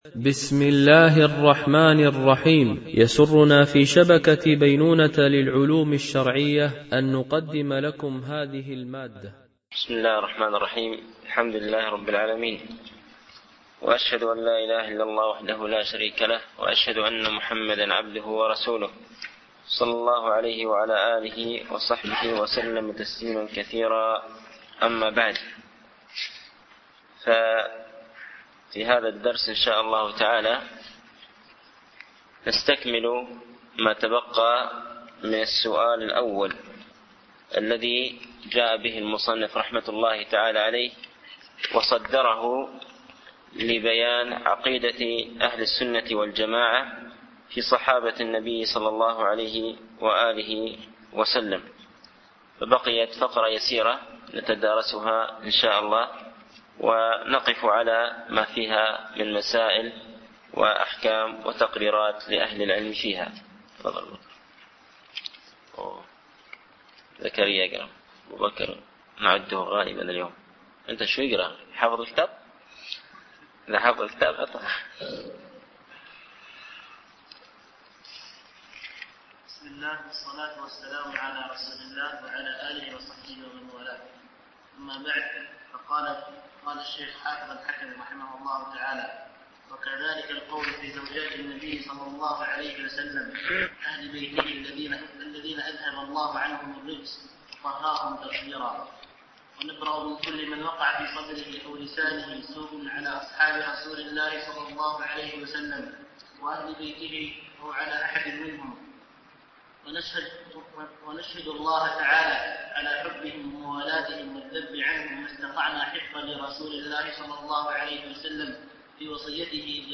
) الألبوم: شبكة بينونة للعلوم الشرعية التتبع: 141 المدة: 53:38 دقائق (12.32 م.بايت) التنسيق: MP3 Mono 22kHz 32Kbps (CBR)